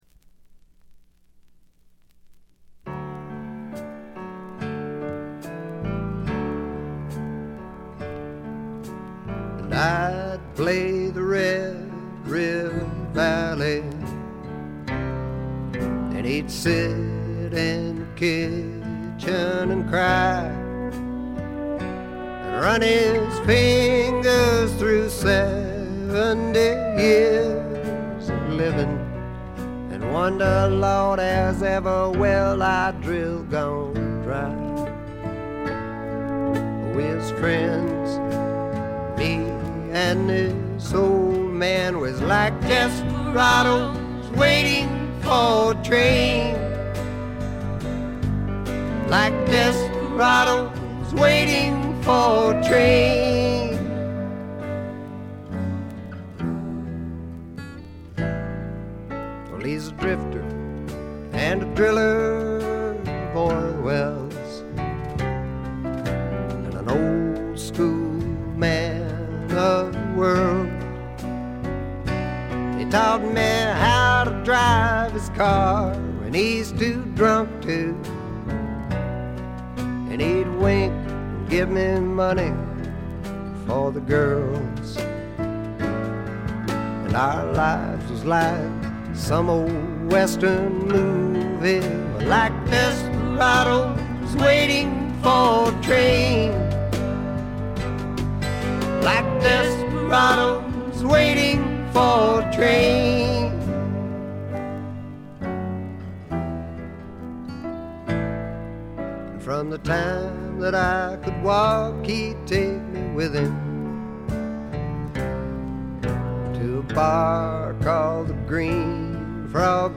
軽微なチリプチ少々。
朴訥な歌い方なのに声に物凄い深さがある感じ。
試聴曲は現品からの取り込み音源です。